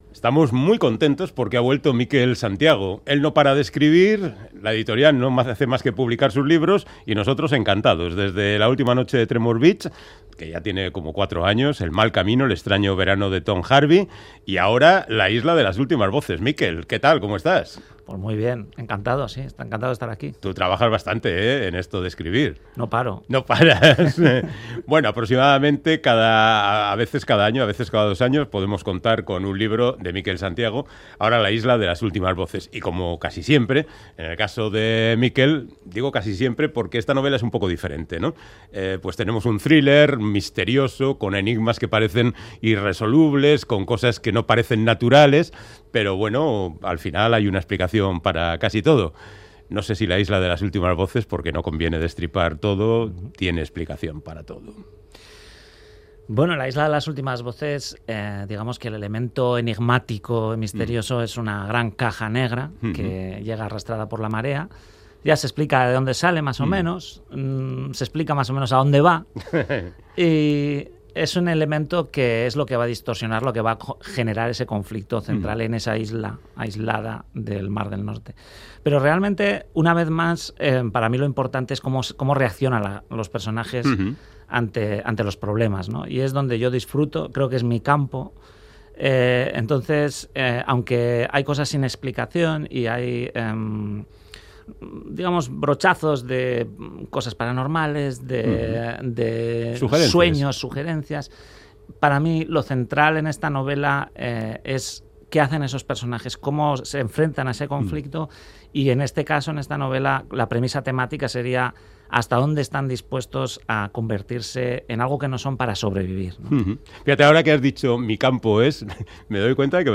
Audio: Charlamos con el vizcaíno Mikel Santiago sobre su nueva novela, La isla de las últimas voces, un thriller de misterio que se adentra en el fantástico gracias al contenido de una caja sorprendente